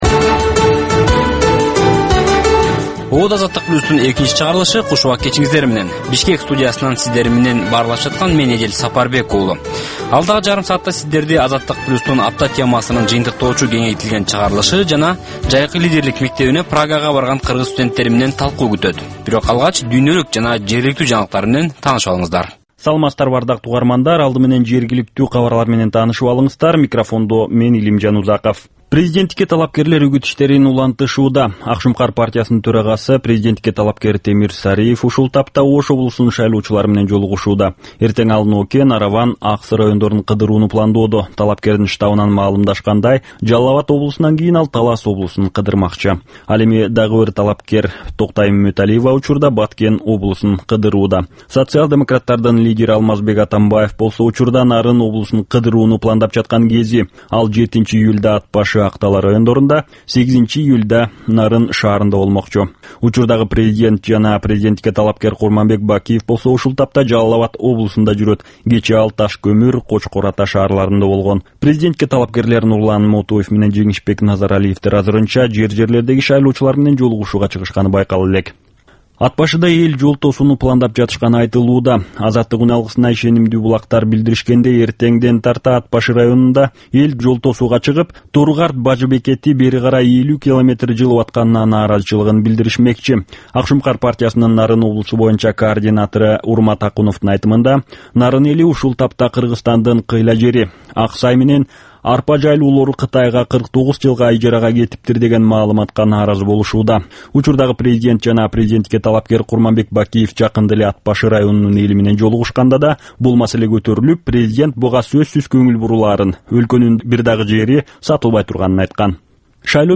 Бул жаштарга арналган кечки үналгы берүү жергиликтүү жана эл аралык кабарлардан, репортаж, маек, баян жана башка берүүлөрдөн турат. "Азаттык үналгысынын" бул жаштар берүүсү Бишкек убактысы боюнча саат 21:30дан 22:00ге чейин обого чыгат.